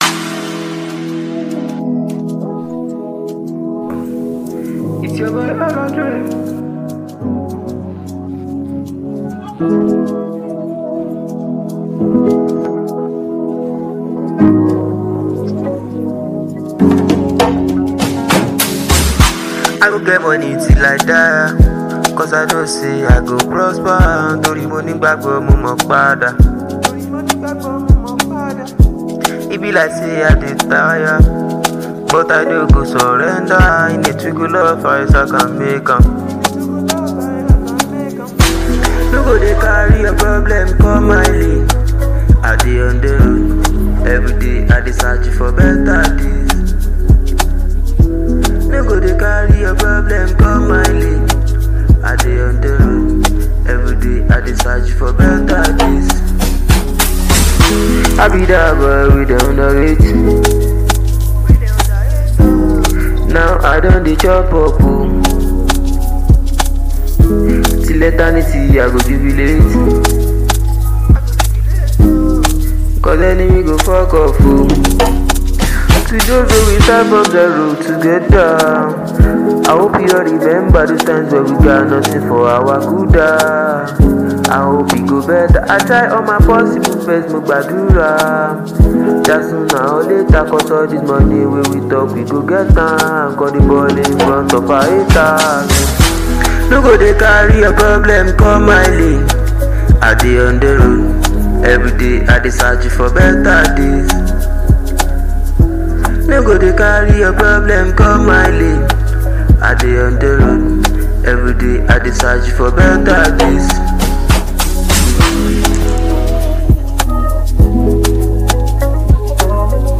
Nigeria fast rising singer
danceable and powerful